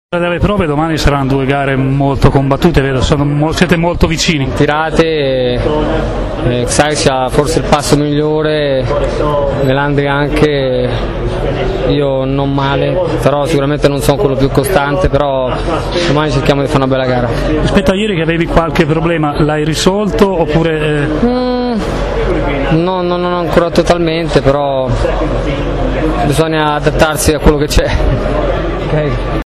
ascolta la sua interista) vince la sua prima Superpole del 2012 e sembra aver superato il momento di crisi culminato a Mosca con la perdita del primato in classifica.